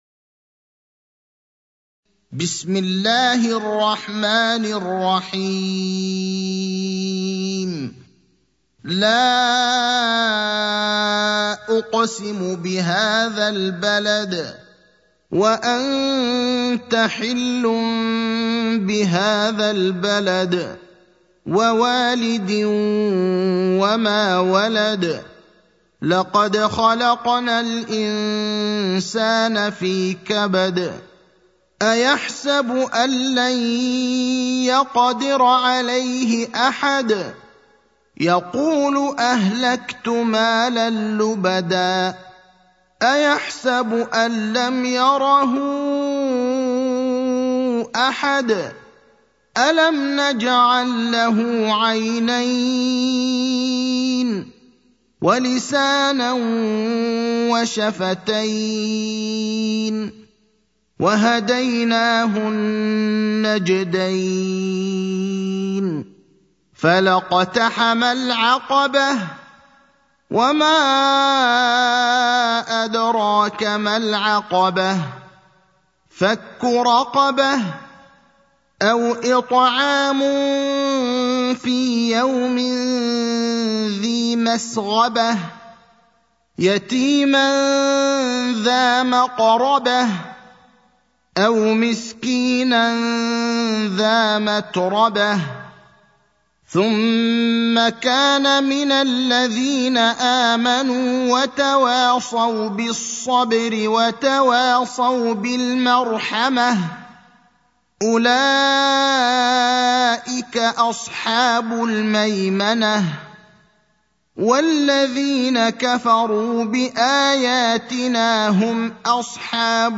المكان: المسجد النبوي الشيخ: فضيلة الشيخ إبراهيم الأخضر فضيلة الشيخ إبراهيم الأخضر البلد (90)